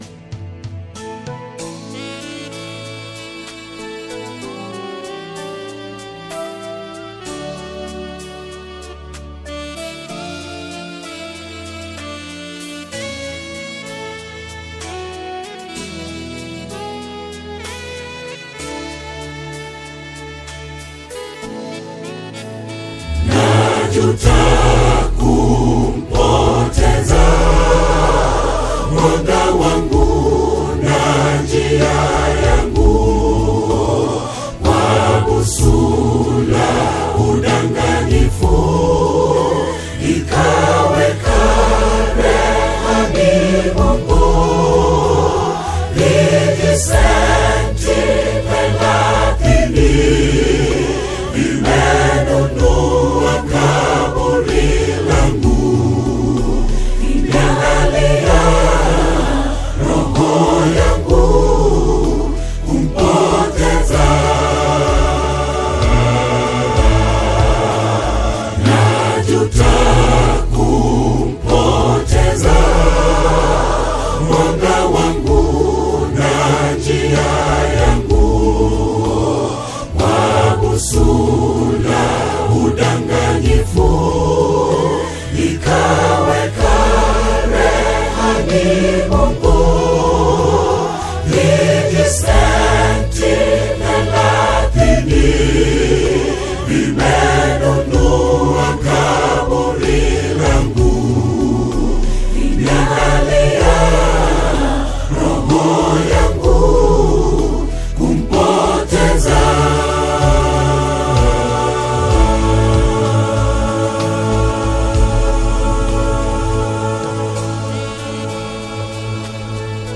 The Kenyan choral community
a premier Kenyan vocal ensemble